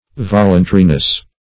Search Result for " voluntariness" : The Collaborative International Dictionary of English v.0.48: Voluntariness \Vol"un*ta*ri*ness\, n. The quality or state of being voluntary; spontaneousness; specifically, the quality or state of being free in the exercise of one's will.